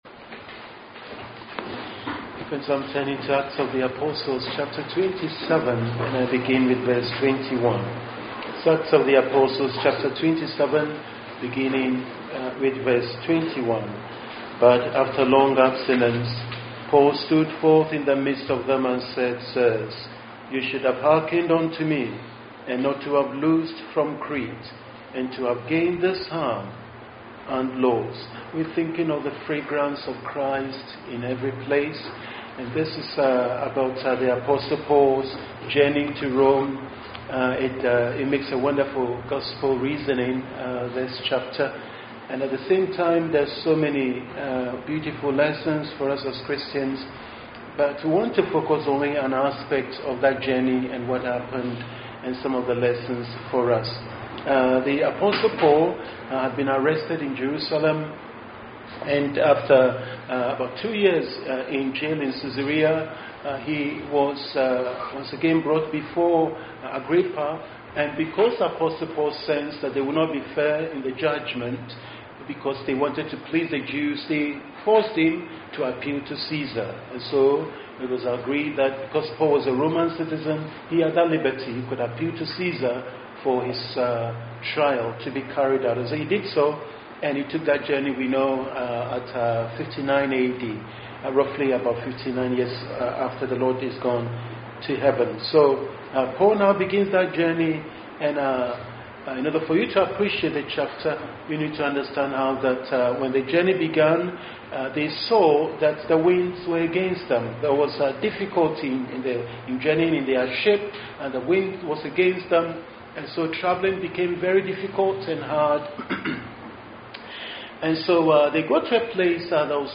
Teaching Sermons – Grace Reformed Baptist Church